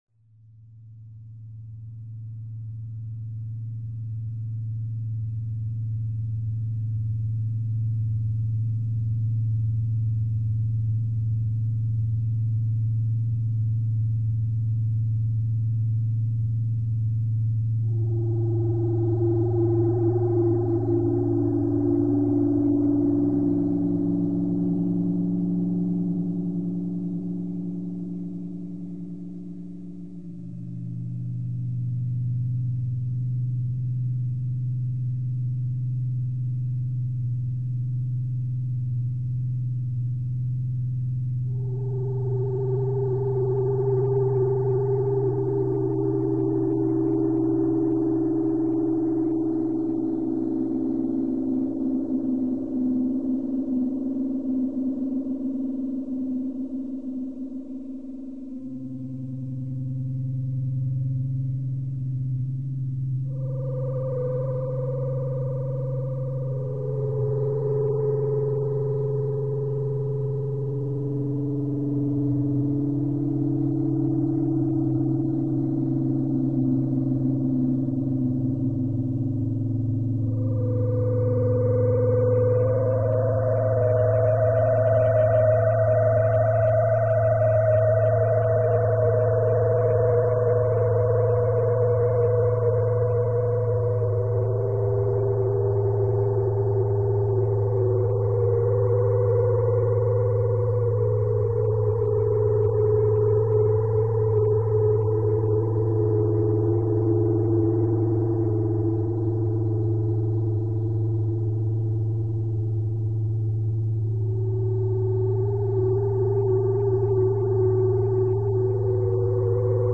8 electro-acoustic compositions